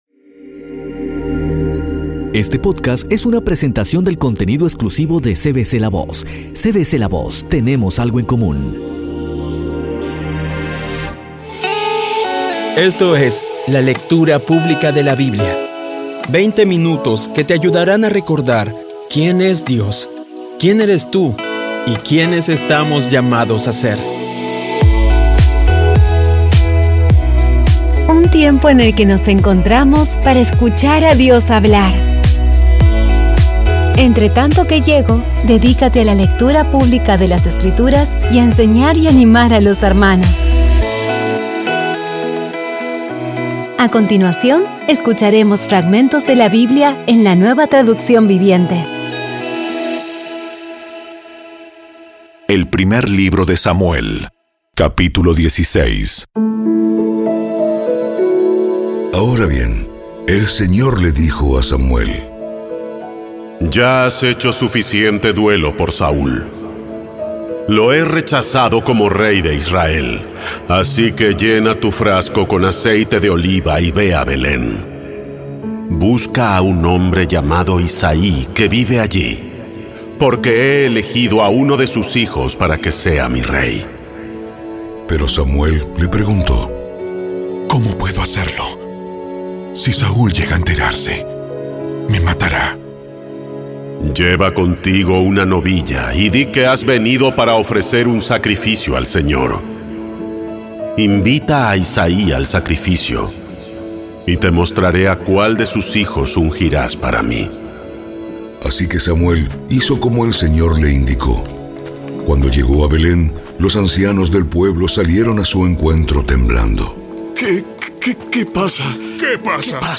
Audio Biblia Dramatizada Episodio 10